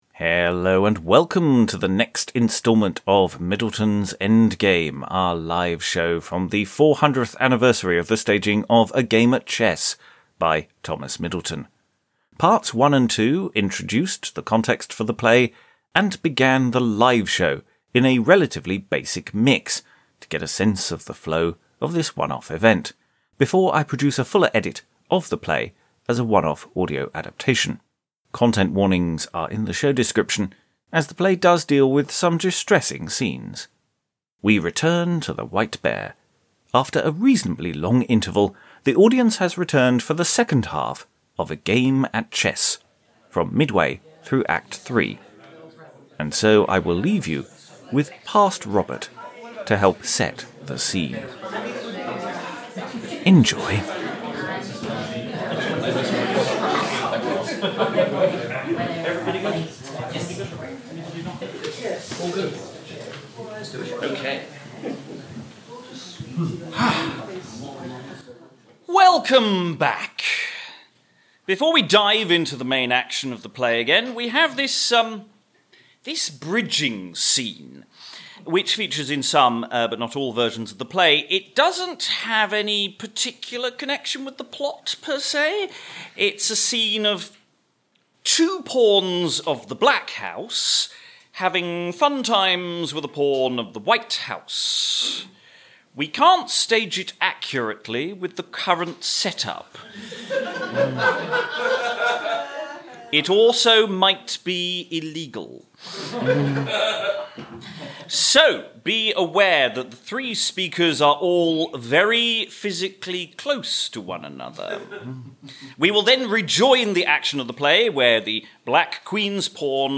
The second half of Thomas Middleton's scandalous play, performed live for the 400th anniversary.
This is episode 3, which covers the second half of the live performance of A Game at Chess itself - from midway into Act 3.
It is a relatively simple mix of the play, a fuller mix with extra bells and whistles will follow anon.